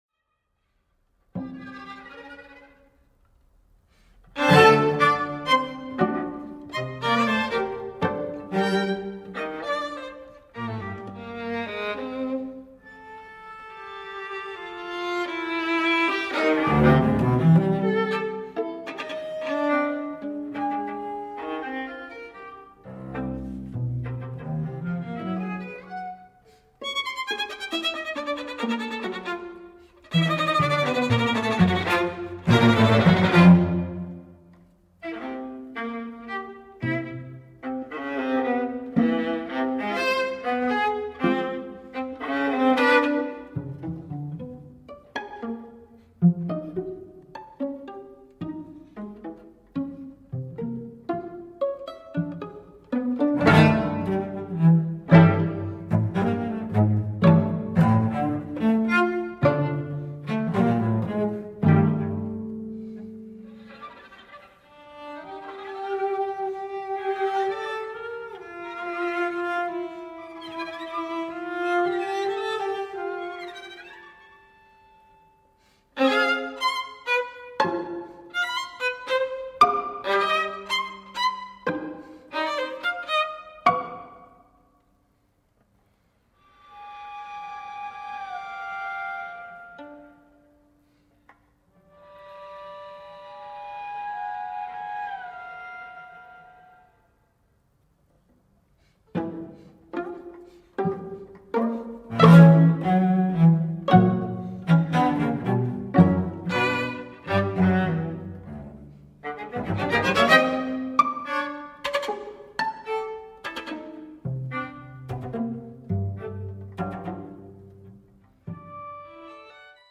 A tempo di tango